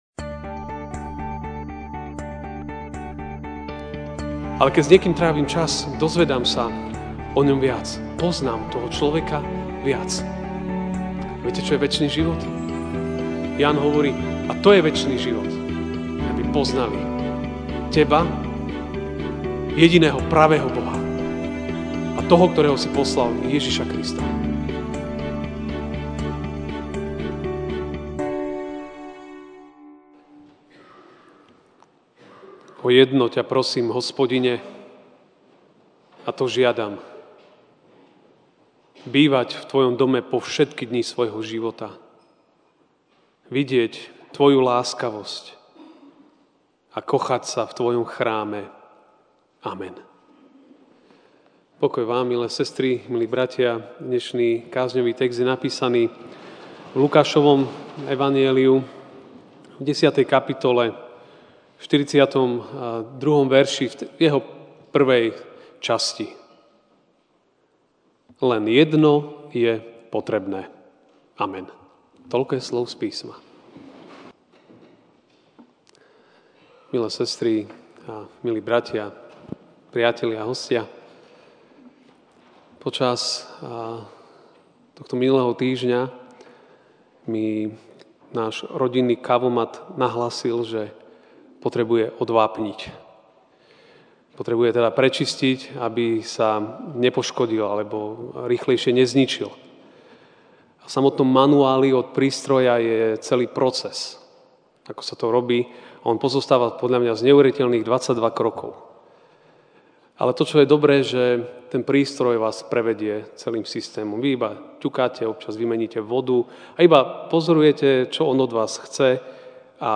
MP3 SUBSCRIBE on iTunes(Podcast) Notes Sermons in this Series Ranná kázeň: Len jedno je potrebné!